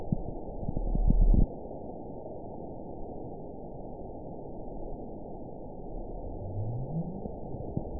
event 921906 date 12/21/24 time 19:01:29 GMT (4 months, 3 weeks ago) score 8.88 location TSS-AB04 detected by nrw target species NRW annotations +NRW Spectrogram: Frequency (kHz) vs. Time (s) audio not available .wav